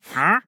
Minecraft Version Minecraft Version latest Latest Release | Latest Snapshot latest / assets / minecraft / sounds / mob / wandering_trader / idle5.ogg Compare With Compare With Latest Release | Latest Snapshot